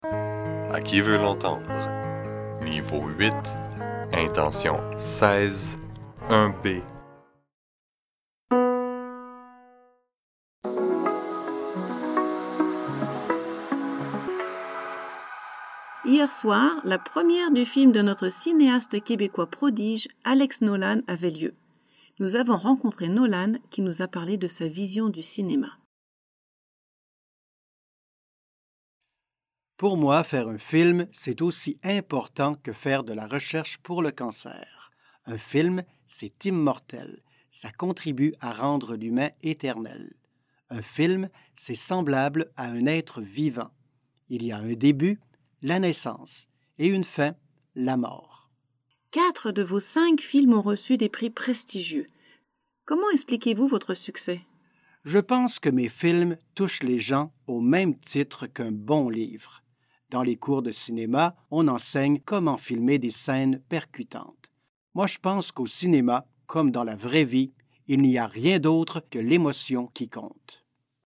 1B – Entrevue avec un cinéaste